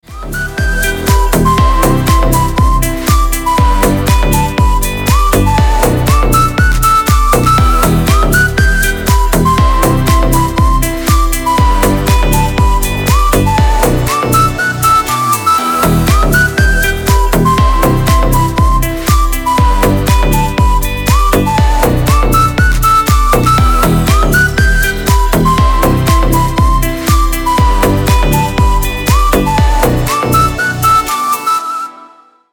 Dance новинки в рингтонах
• Песня: Рингтон, нарезка